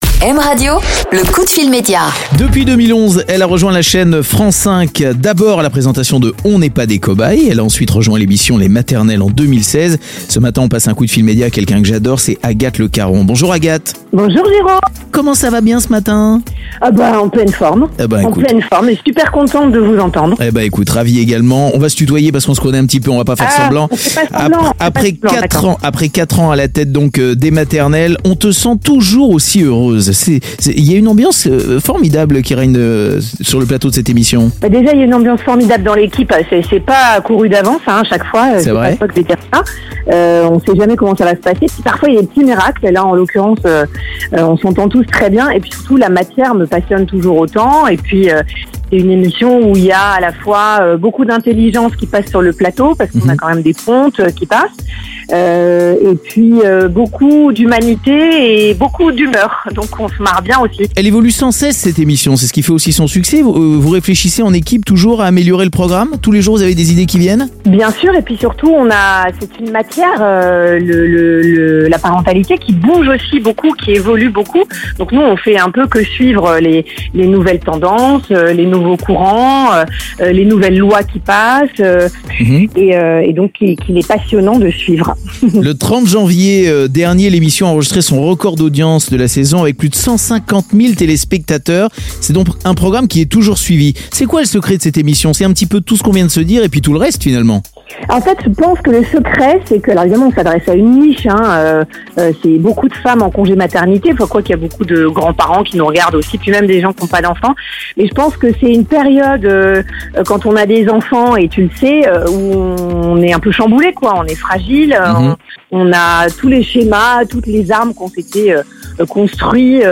Mardi 10 mars, Jérôme Anthony passe un coup de fil à Agathe Lecaron, pour nous parler de l'émission "Les Maternelles", tous les matins sur France 5.